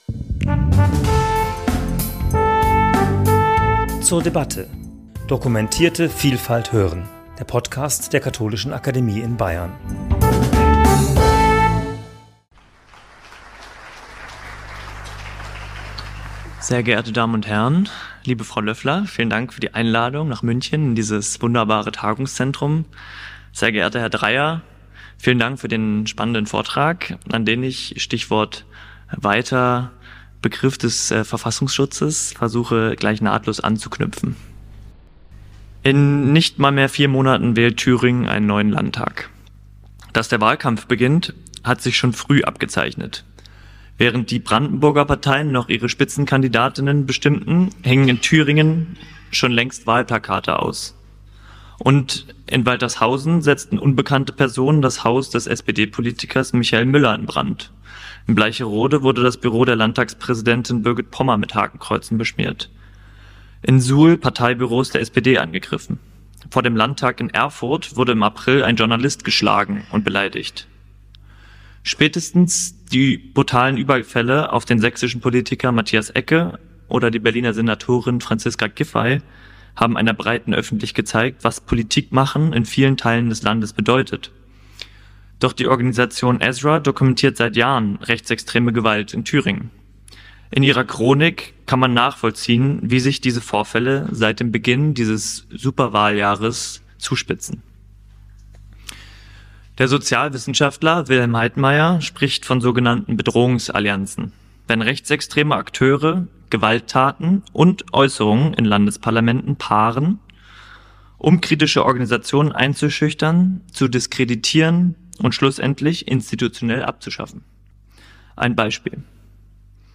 Dieser Problematik wollten wir im Rahmen eines Vortrags- und Diskussionsabends nachgehen.